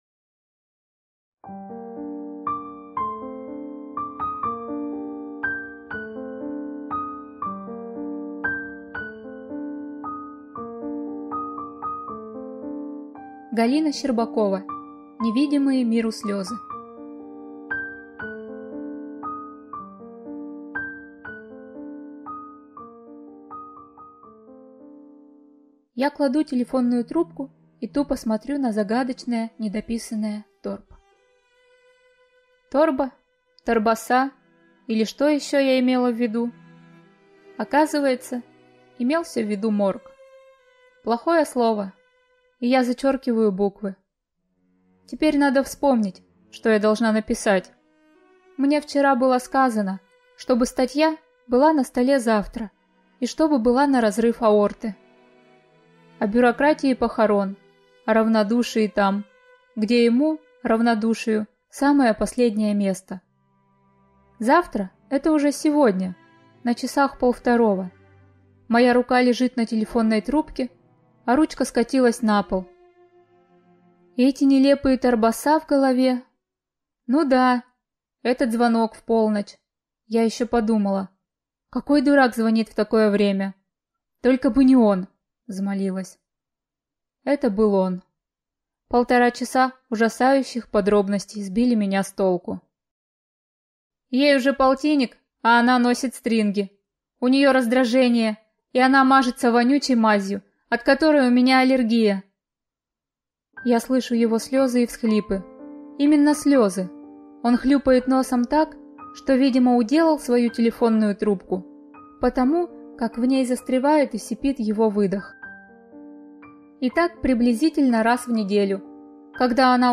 Аудиокнига Невидимые миру слезы | Библиотека аудиокниг